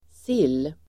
Uttal: [sil:]